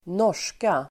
Uttal: [²n'år_s:ka]